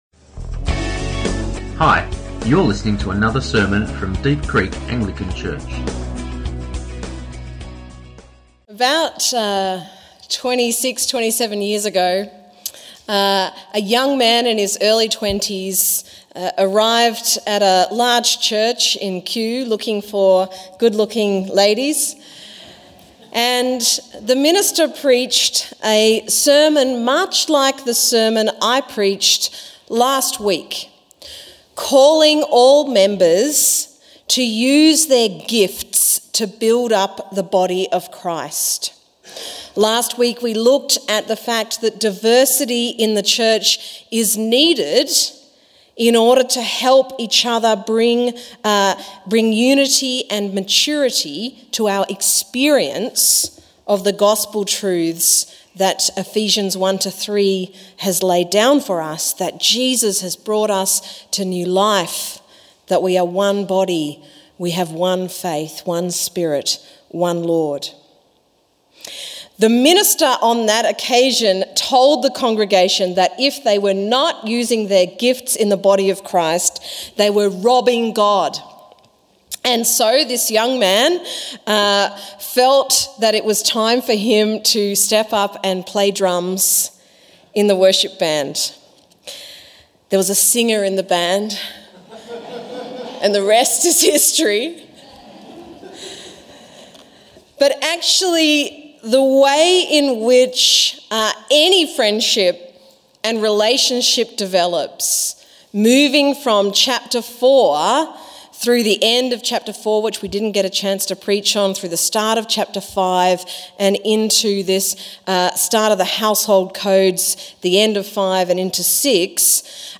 Sermons | Deep Creek Anglican Church